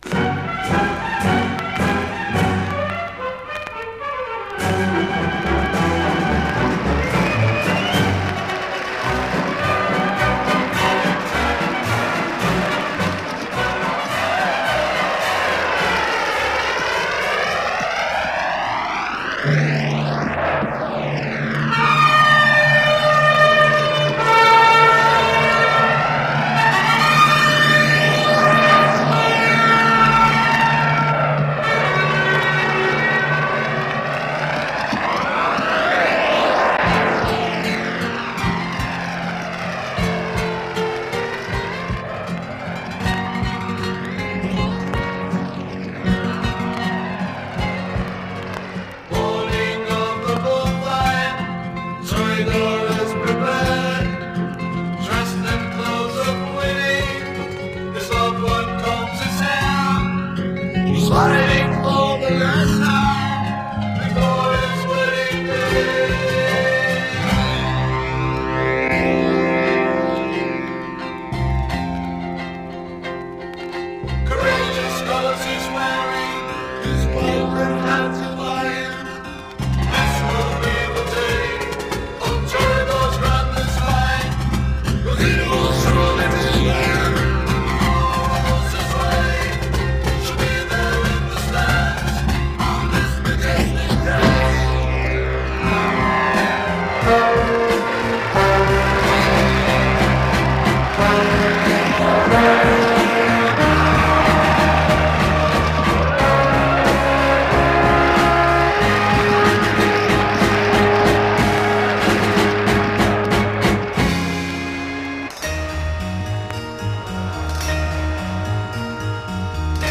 International Jazz Pop